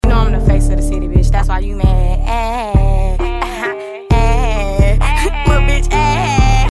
soundnya ngeledek bgt😭 sound effects free download